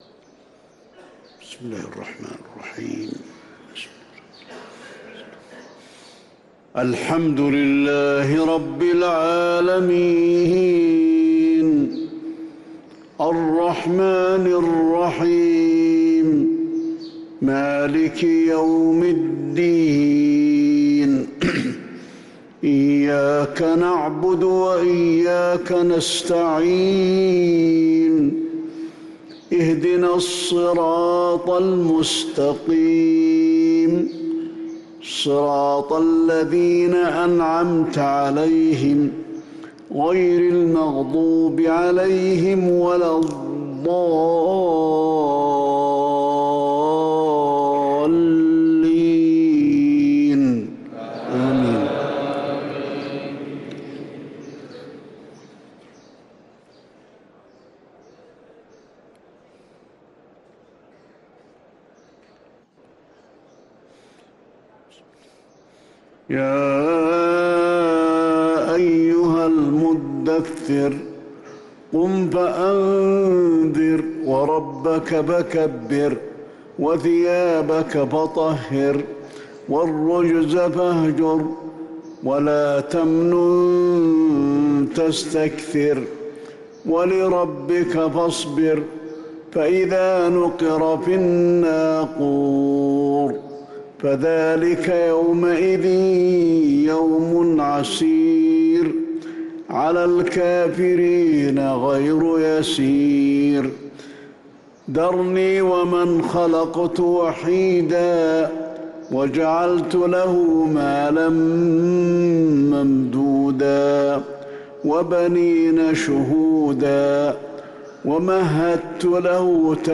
صلاة الفجر للقارئ علي الحذيفي 17 جمادي الآخر 1445 هـ
تِلَاوَات الْحَرَمَيْن .